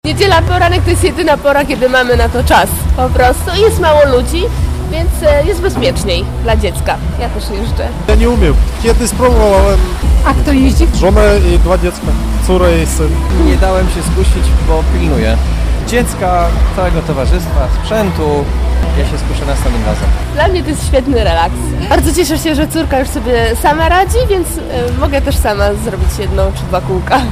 Na lodowisku na poznańskiej Malcie ślizgają się od rana.
- mówili w rozmowie z naszą reporterką.